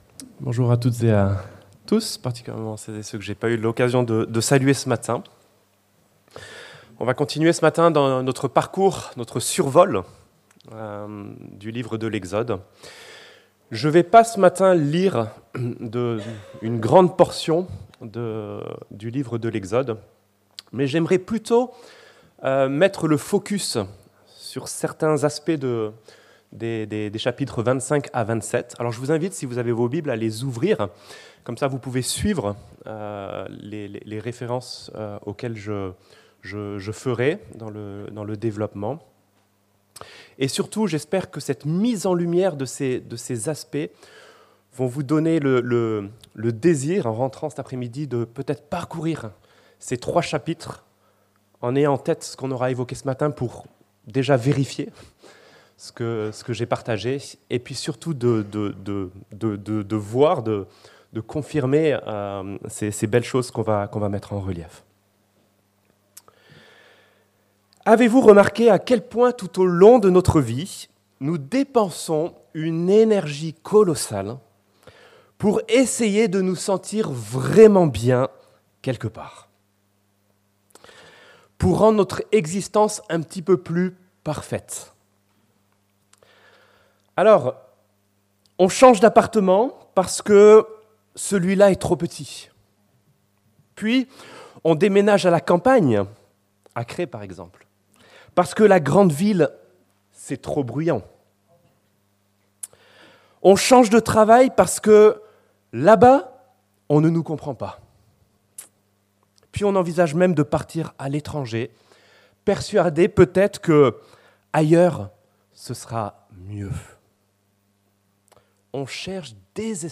Le Tabernacle, un avant-goût de la maison - Prédication de l'Eglise Protestante Evangélique de Crest sur le livre de l'Exode